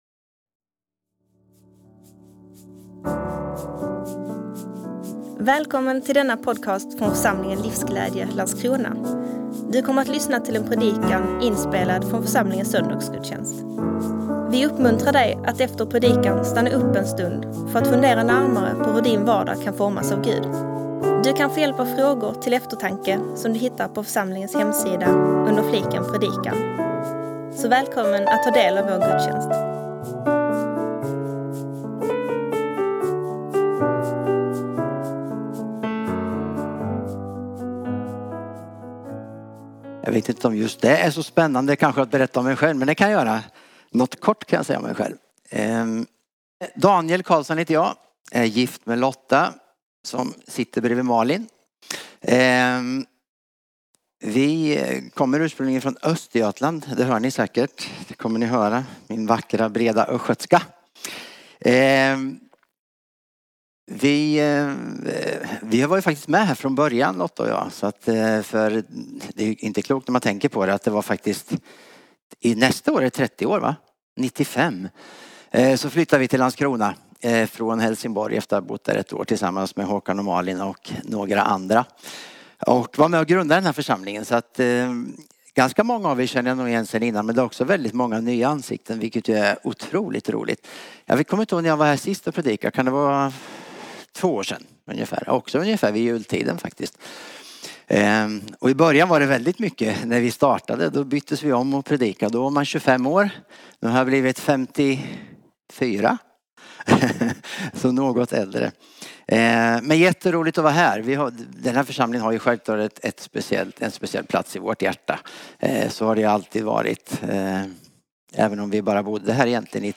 8 december 2024 Guds rike är nära Predikant